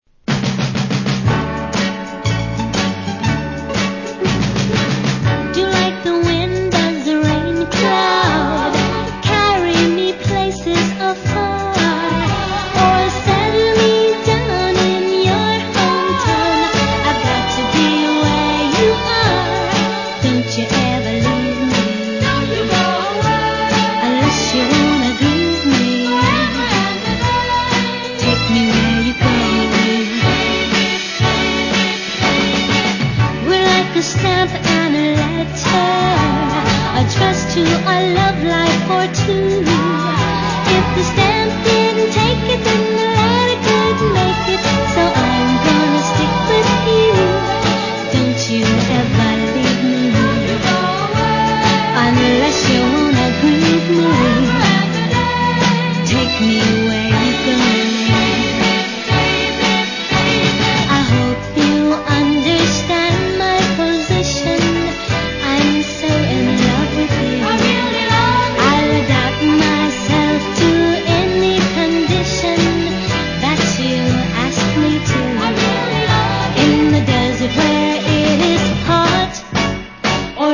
Very rare and previously unreleased Northern dancer.